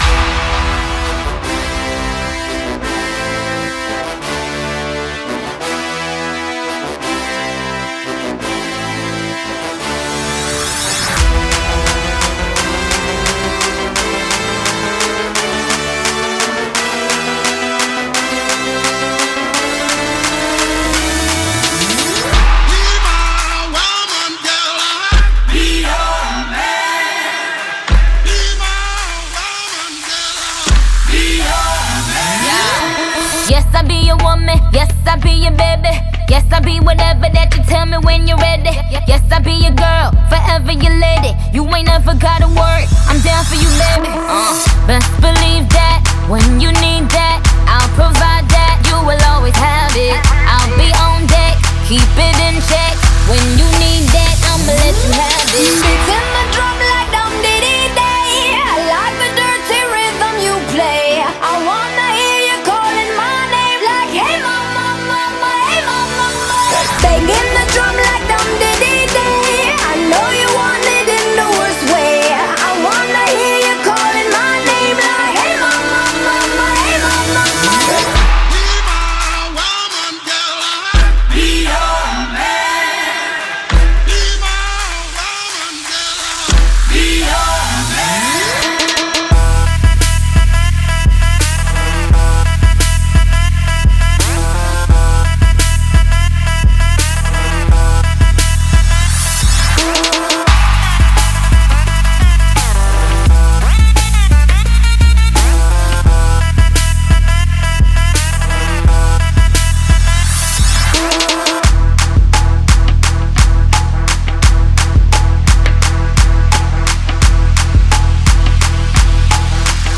BPM172
Audio QualityLine Out